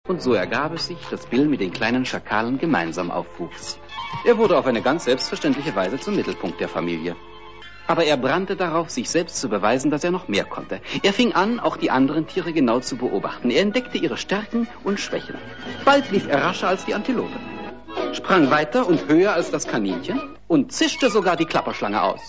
Das Verwirrende ist nur, dass bei Pecos mehrere Sprecher zum Einsatz kommen. Auch im Original wechseln sich die Cowboys am Lagerfeuer mit der Geschichte ab, daher dieses Wirrwarr.
Sample 4 Hauptsprecher "Pecos Bill" (DF 1952)
Ich meine, ganz leichte unterschiedliche regionale Einfärbungen in der Sprache zu hören.